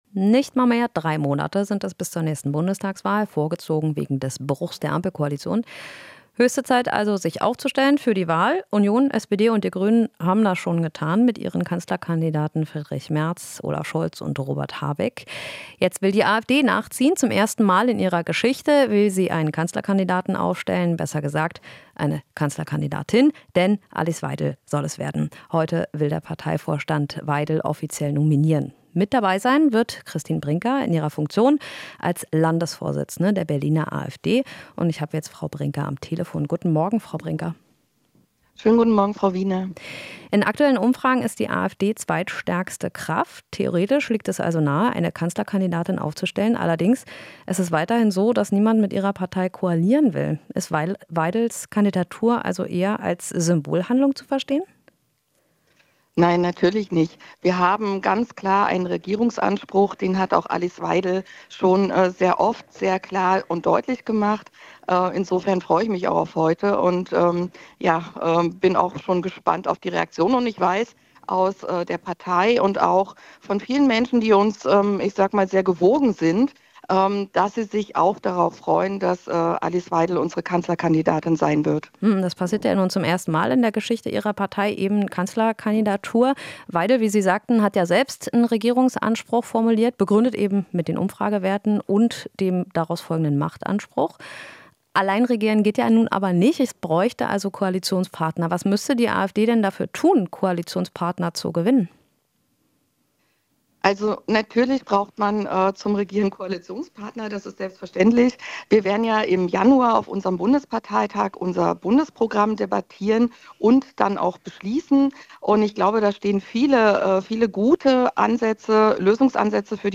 Interview - Brinker (AfD): Weidel kann unser Programm gut erklären